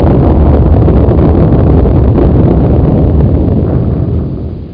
1 channel
bomb.mp3